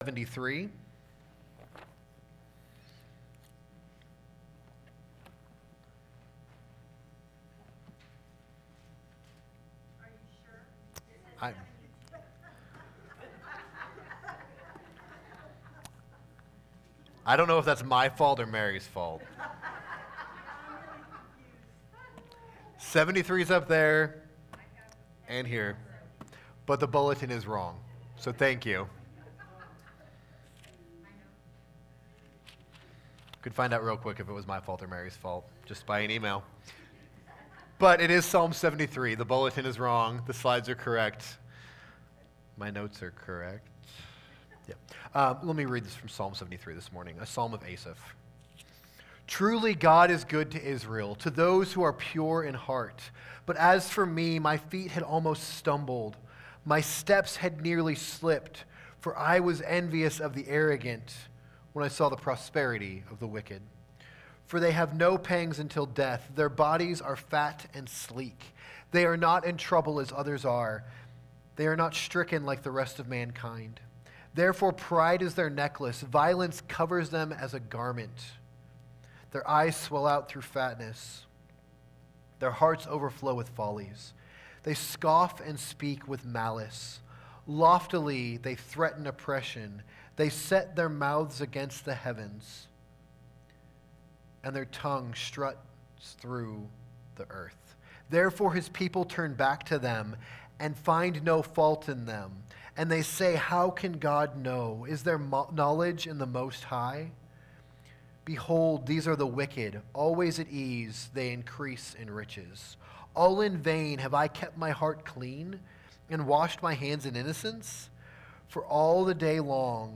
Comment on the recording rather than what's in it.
Summer in the Psalms Sunday Morning Service